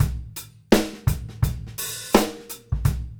GROOVE 220LR.wav